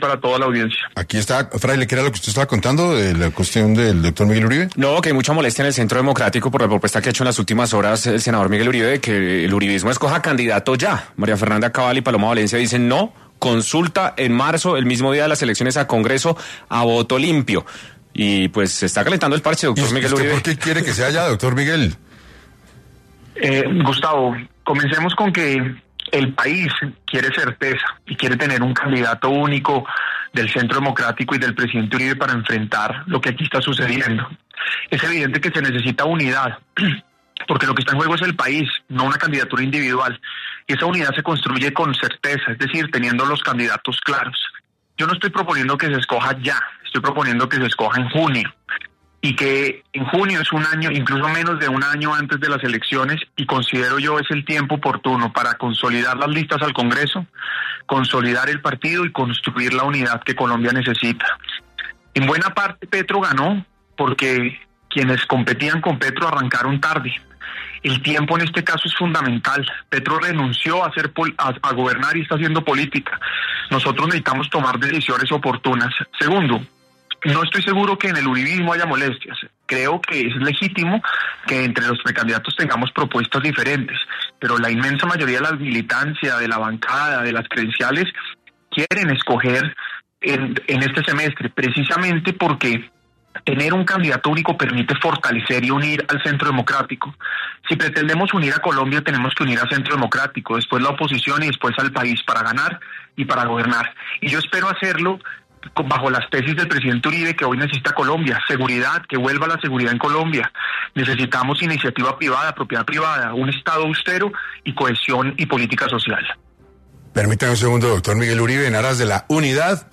Los políticos del partido Centro Democrático, Paloma Valencia, Miguel Uribe y María Fernanda Cabal, Hablaron para 6AM sobre los proyectos que tiene como partido para lograr ganar las elecciones del 2026, Pero ¿van para el mismo norte o tienen diferencias?